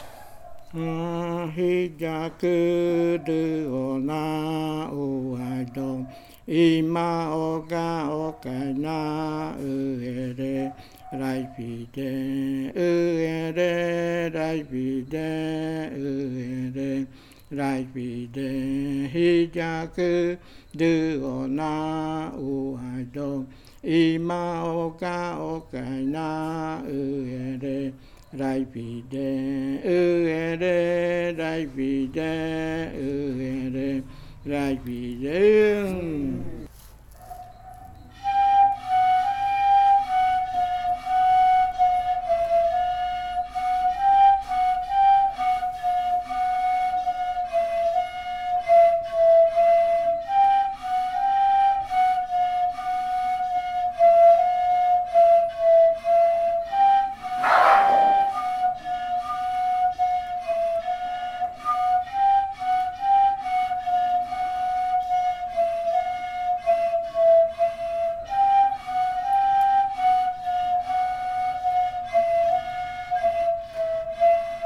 Leticia, Amazonas, (Colombia)
Canto Jiyakɨ dɨona (lengua murui) e interpretación del canto en pares de reribakui.
Jiyakɨ dɨona chant (Murui language) and performance of the chant in reribakui flutes.
Flautas de Pan y cantos de fakariya del grupo Kaɨ Komuiya Uai